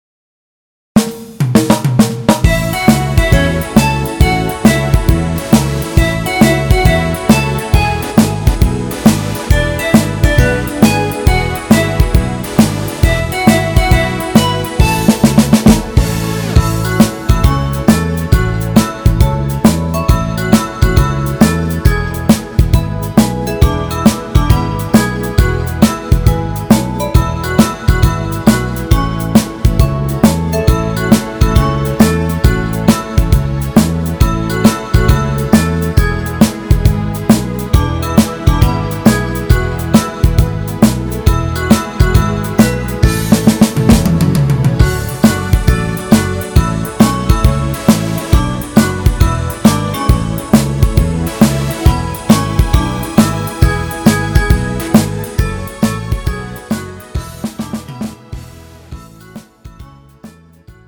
음정 A 키
장르 가요 구분 Pro MR